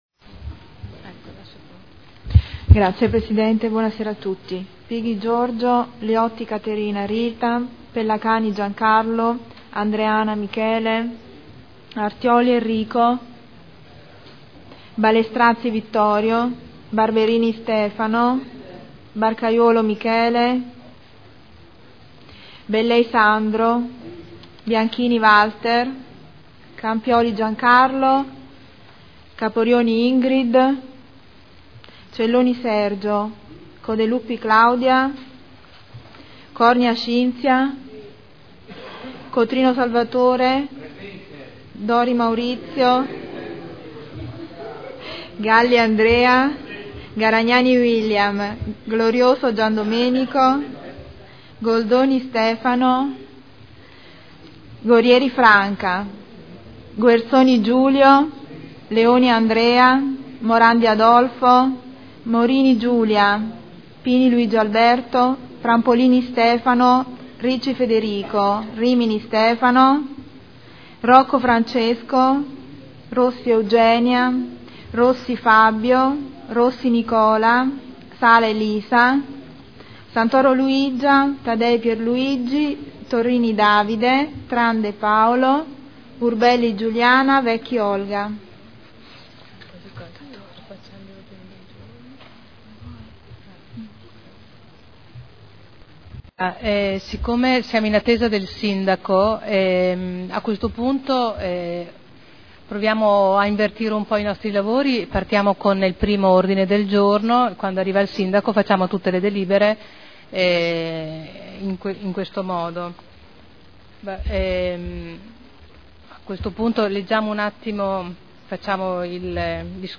Seduta del 12 dicembre Apertura del Consiglio Comunale Appello
Segretario Generale